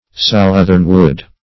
Southernwood \South"ern*wood`\, n. (Bot.)